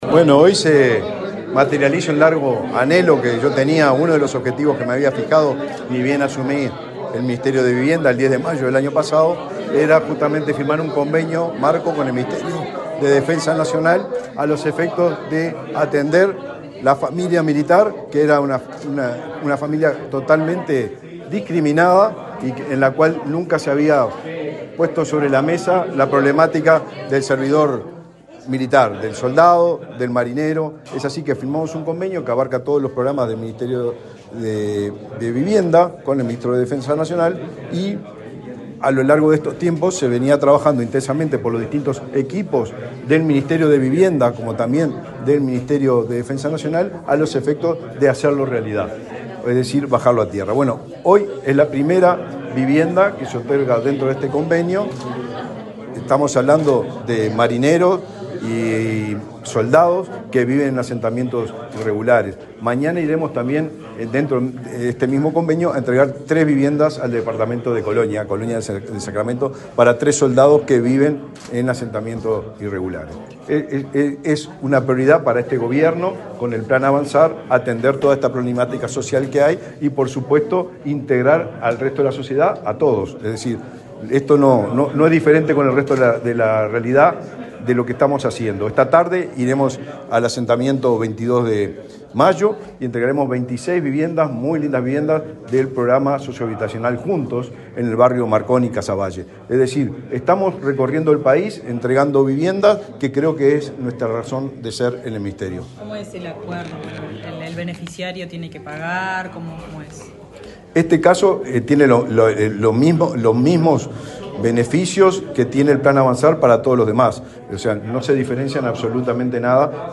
Declaraciones del ministro de Vivienda, Raúl Lozano
Declaraciones del ministro de Vivienda, Raúl Lozano 22/10/2024 Compartir Facebook X Copiar enlace WhatsApp LinkedIn El ministro de Vivienda, Raúl Lozano, dialogó con la prensa, luego de participar del acto de entrega de una vivienda a un efectivo de la marina que vive en un asentamiento, en el Cerro de Montevideo.